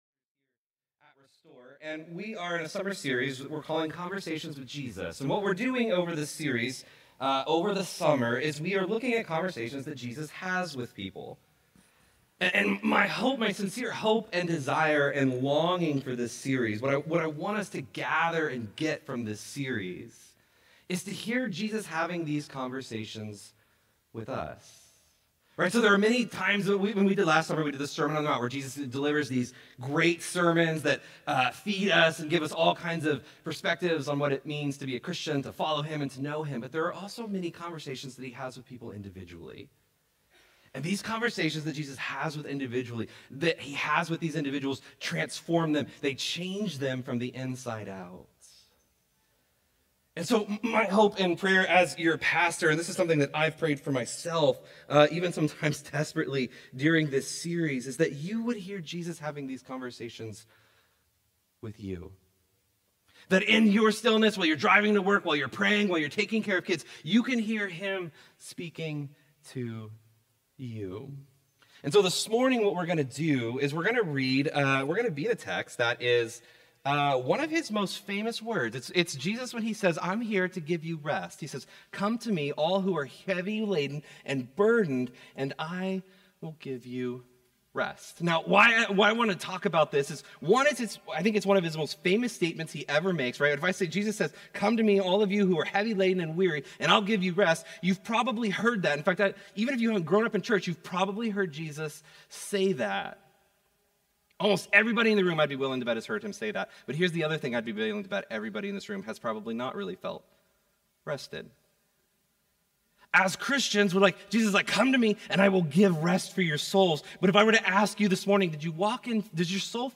Restore Houston Church Sermons The Gospel for Burned-Out People Jul 04 2025 | 00:36:46 1x 00:00 / 00:36:46 Subscribe Share Apple Podcasts Overcast RSS Feed Share Link Embed